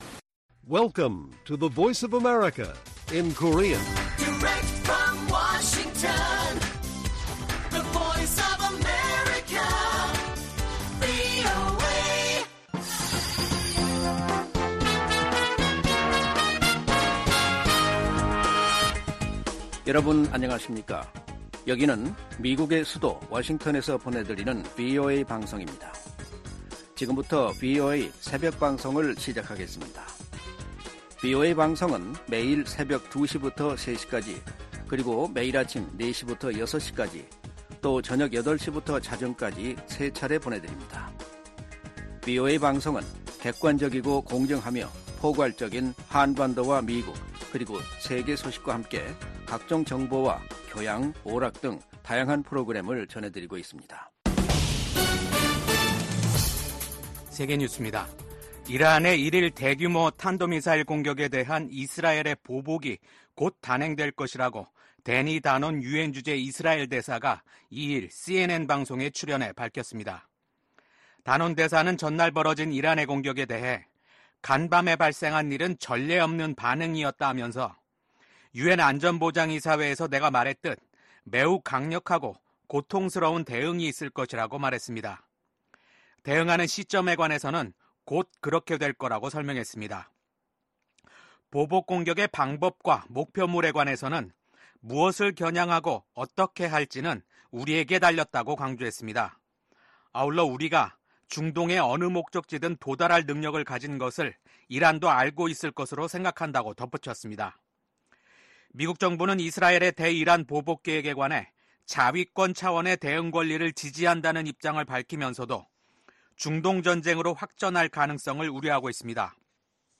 VOA 한국어 '출발 뉴스 쇼', 2024년 10월 4일 방송입니다. 미국 국무부의 커트 캠벨 부장관은 러시아 군대의 빠른 재건이 중국, 북한, 이란의 지원 덕분이라고 지적했습니다. 조 바이든 미국 대통령이 새로 취임한 이시바 시게루 일본 총리와 처음으로 통화하고 미한일 협력을 강화와 북한 문제를 포함한 국제 정세에 대응하기 위한 공조를 재확인했습니다.